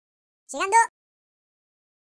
share/hedgewars/Data/Sounds/voices/Default_es/Incoming.ogg
Spanish voice
Incoming.ogg